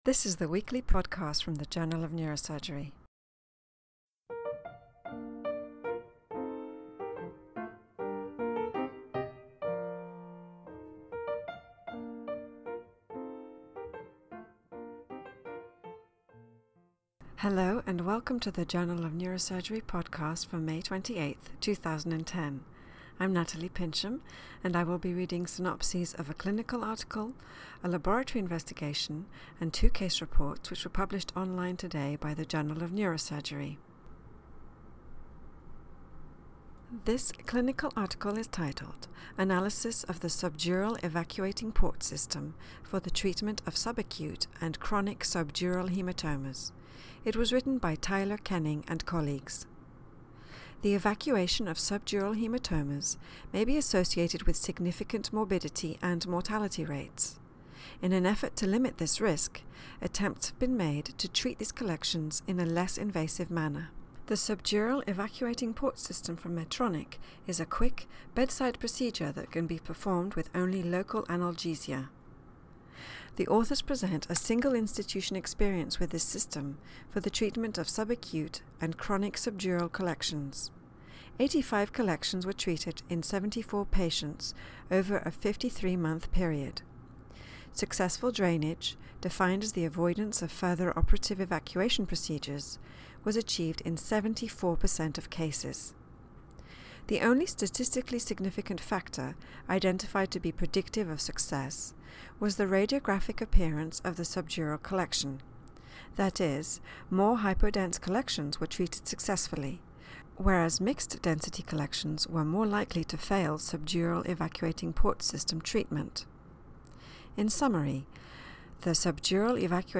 reads synopses of Journal of Neurosurgery articles published online on May 28, 2010.